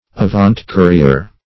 Search Result for " avant-courier" : The Collaborative International Dictionary of English v.0.48: avant-courier \a*vant"-cou`ri*er\, n. [F., fr. avant before + courrier.